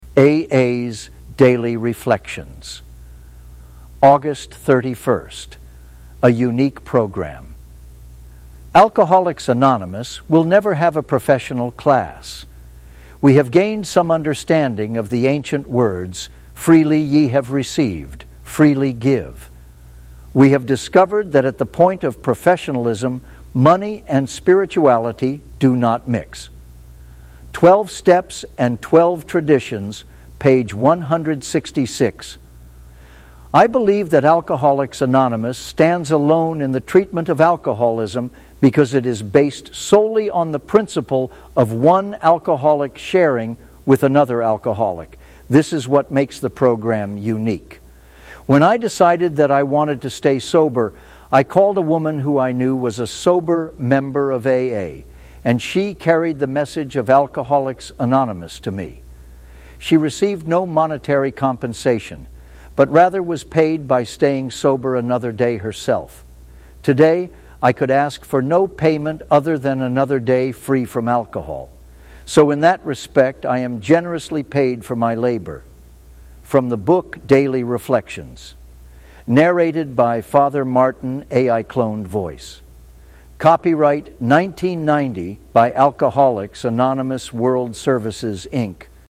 A.I. Cloned Voice.